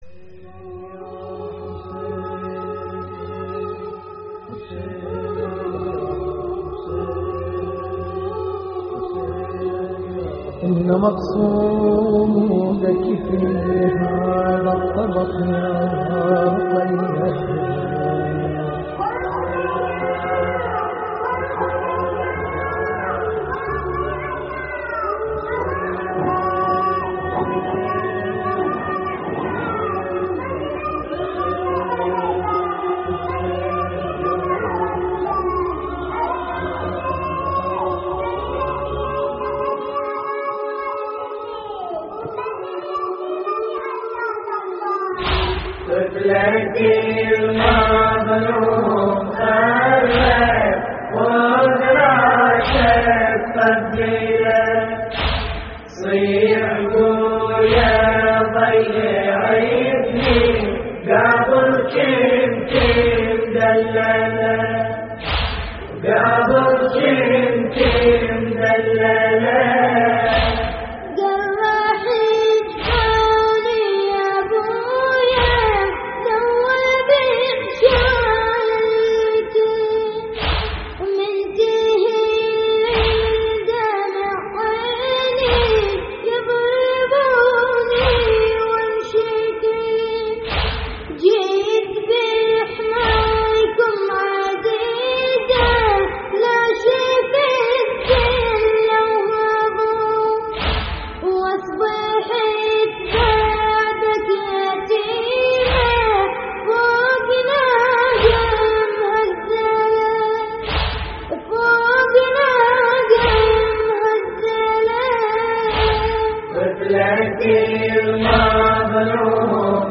استديو